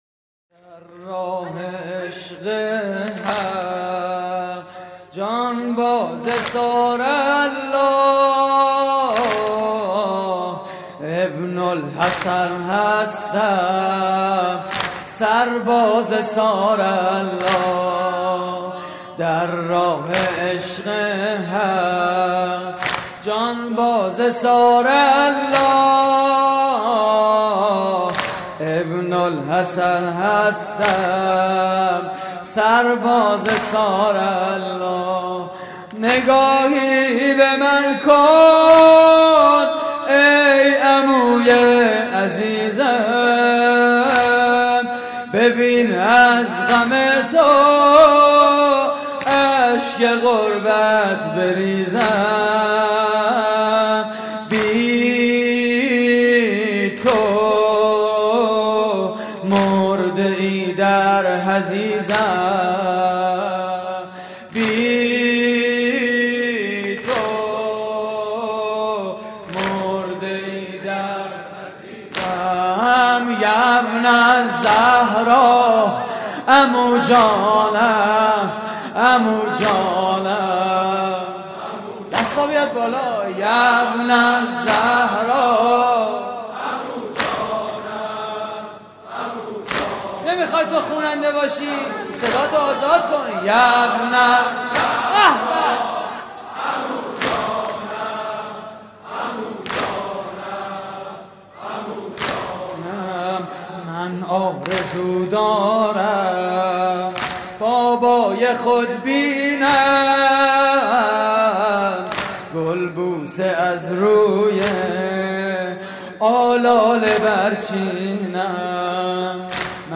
مراسم عزاداری شب پنجم ماه محرم